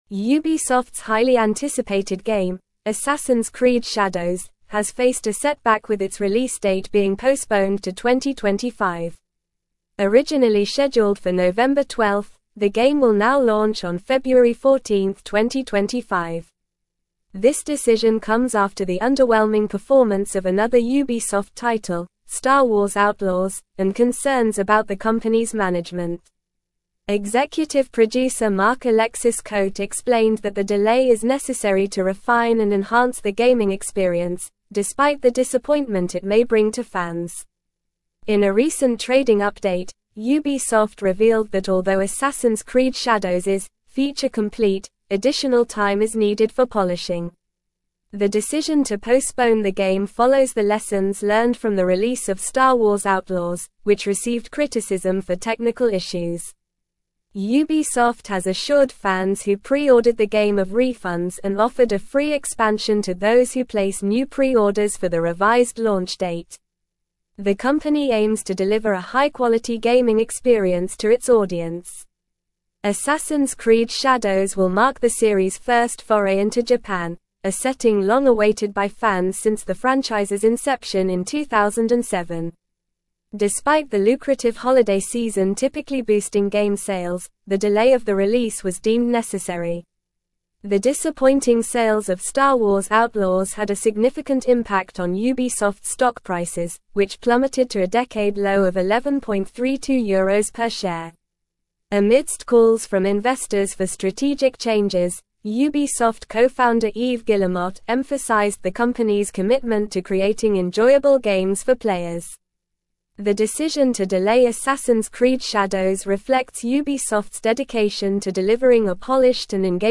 Normal
English-Newsroom-Advanced-NORMAL-Reading-Ubisoft-Delays-Assassins-Creed-Shadows-Release-to-2025.mp3